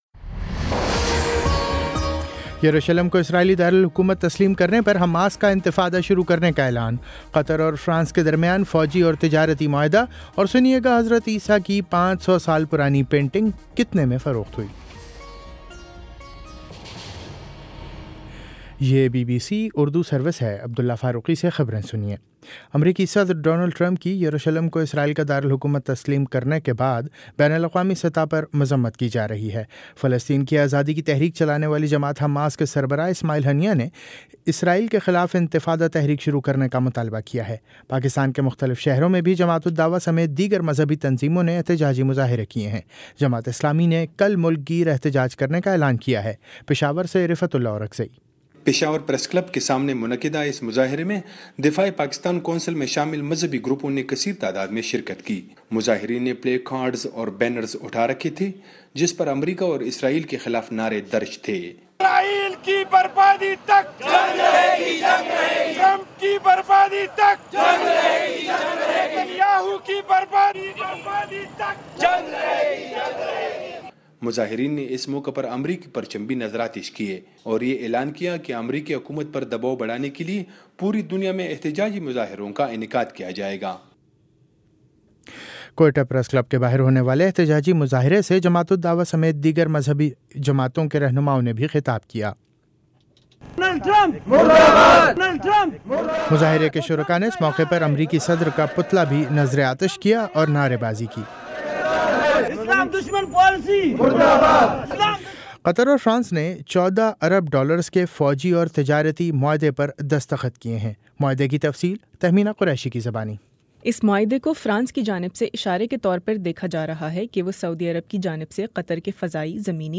دسمبر 07 : شام پانچ بجے کا نیوز بُلیٹن